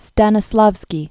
(stănĭ-slävskē, -släf-), Konstantin Originally Konstantin Sergeyevich Alekseyev. 1863-1938.